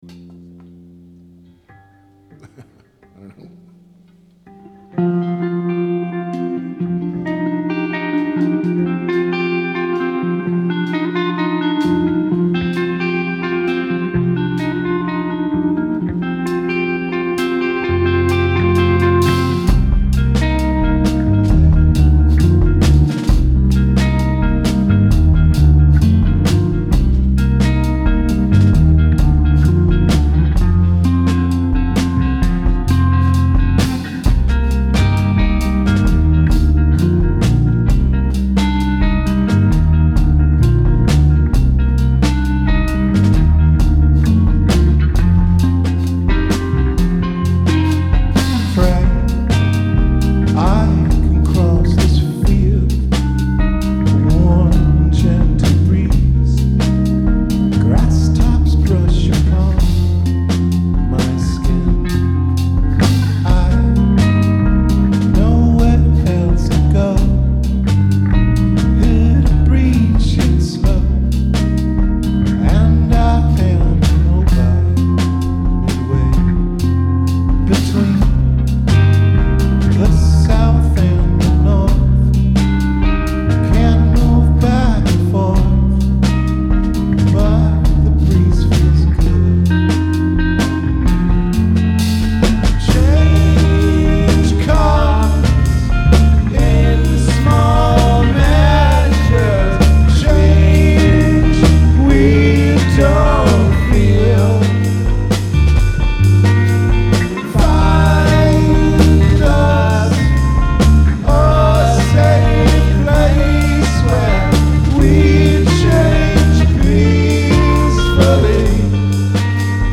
Rehearsal recordings